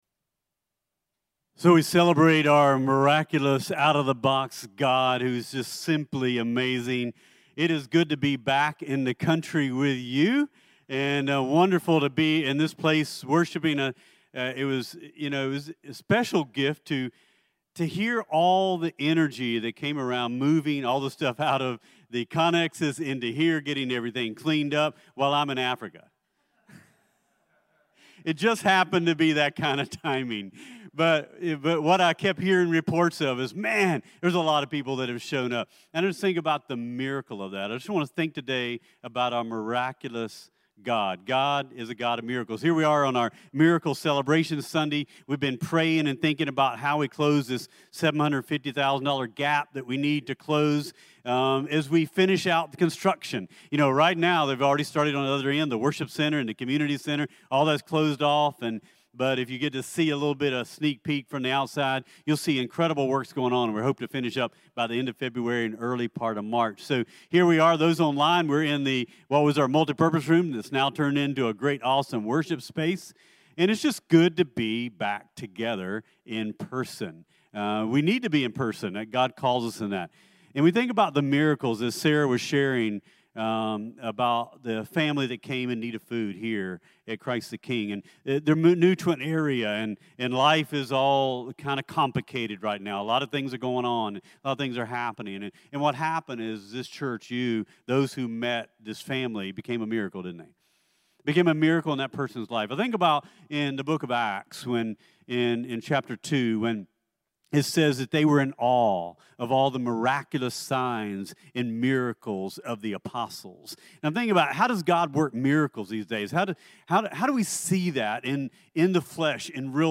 CTK-Dec-11-Clipped-Sermon-.mp3